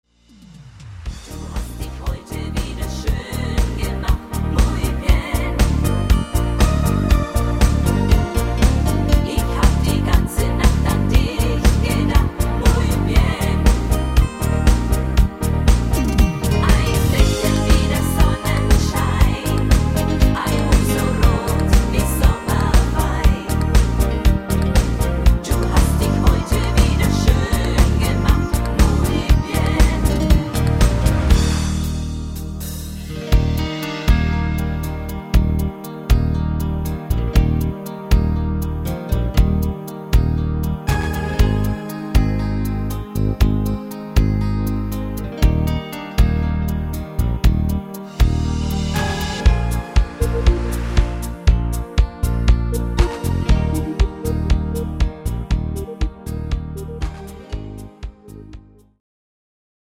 Easy to sing